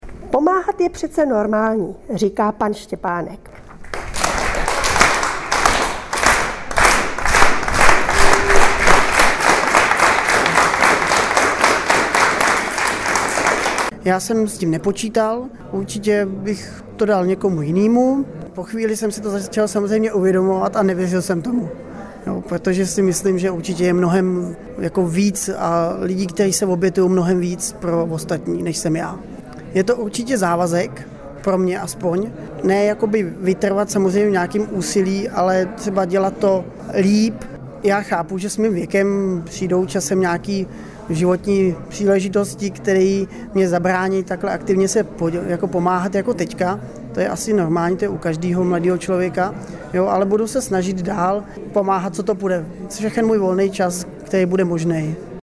Okamžik z předání ceny Ď si v audio podobě můžete